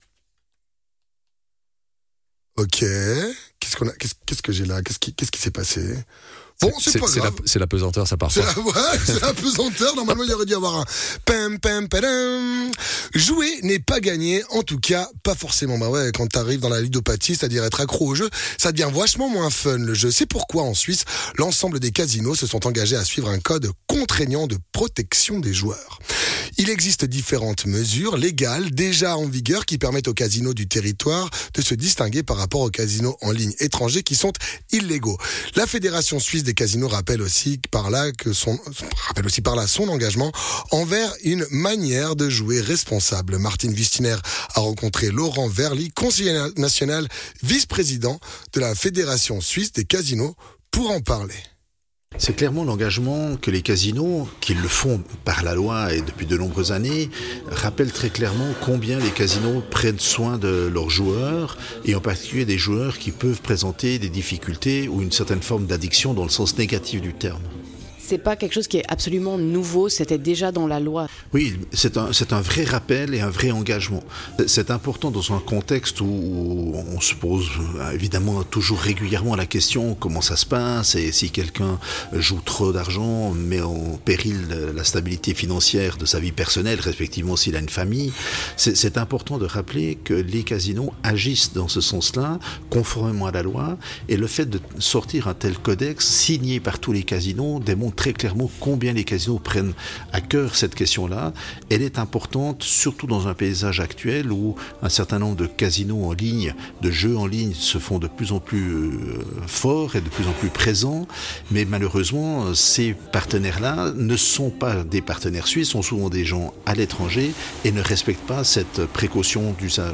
Intervenant(e) : Laurent Wehrli, conseiller national, vice président de la fédération Suisse des casinos